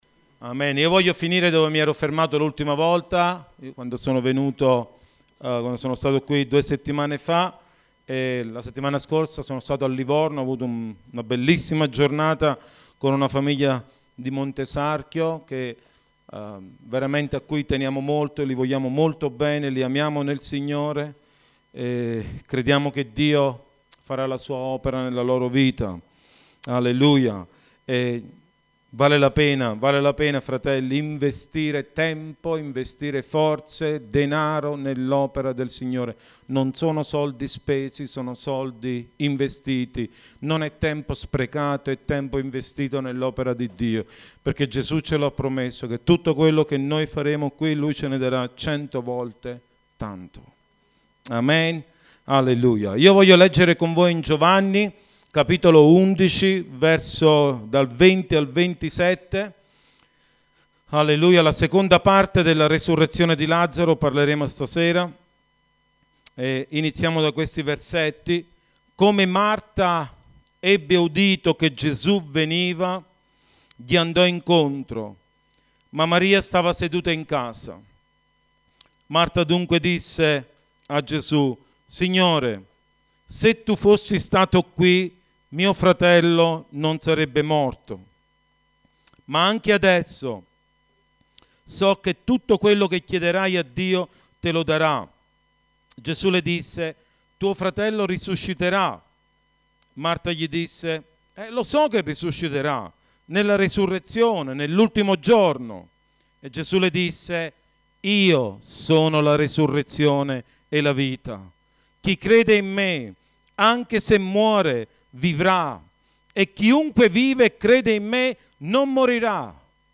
Una predicazione è un discorso fatto da un membro della chiesa. Le predicazioni riguardano argomenti biblici, teologici o morali.